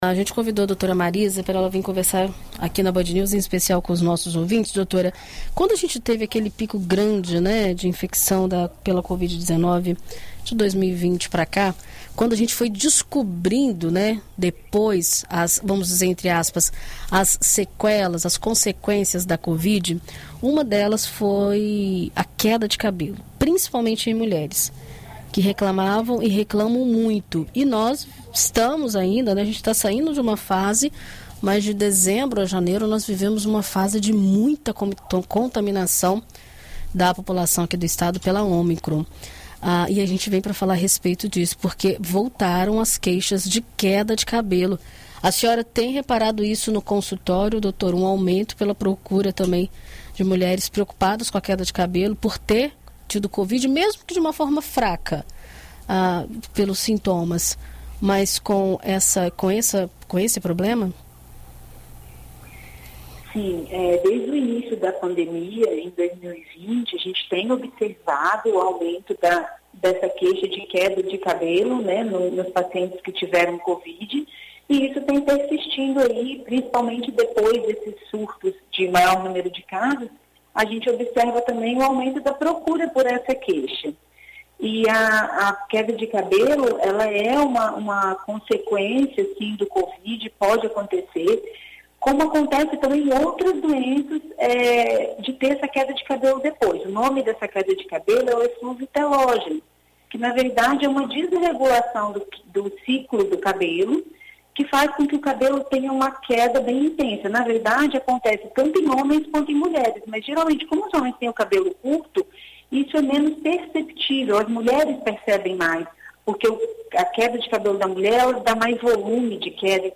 Muitas pessoas têm se queixado da ocorrência de queda do cabelo após terem contraído a covid-19. Segundo especialista, o efeito possui relação com a doença e pode durar até seis meses após a cura.